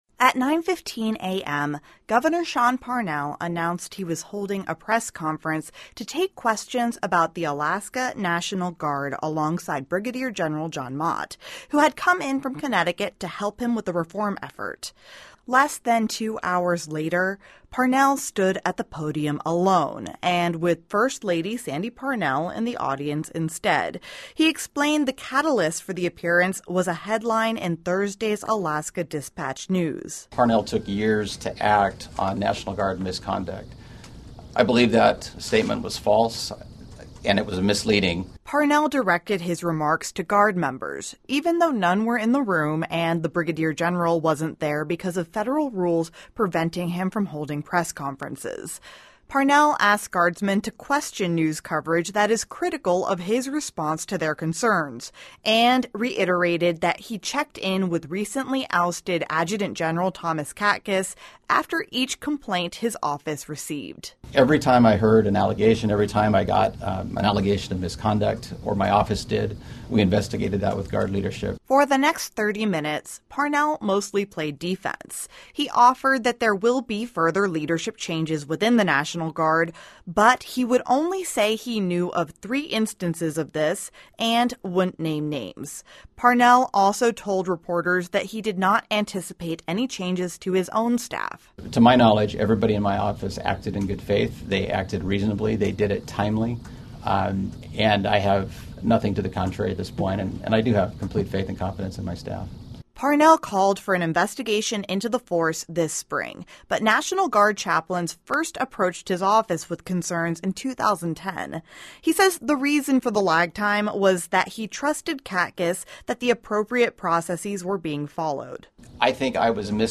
A half hour after Parnell cut the press conference short to catch a flight, a group of a dozen protestors gathered outside his Anchorage office.